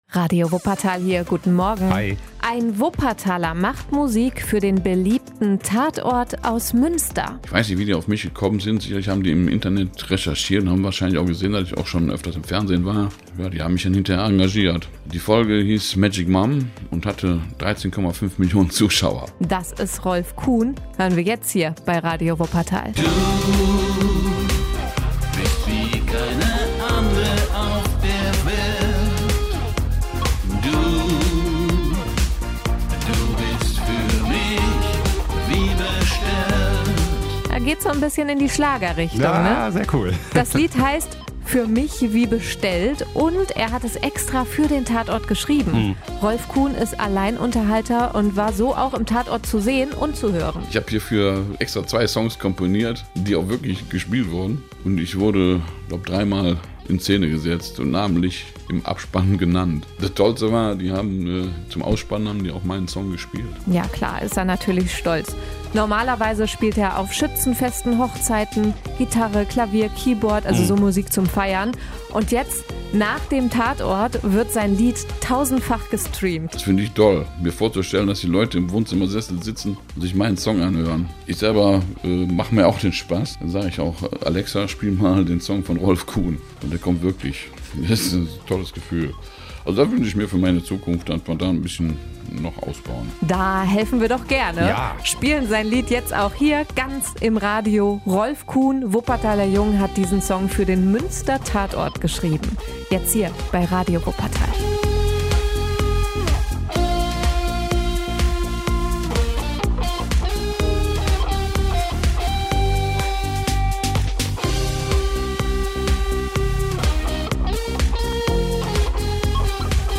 Partymusik aus den Genres Pop, Pop-Schlager, Rock, Kölsch, Oldies und Charts.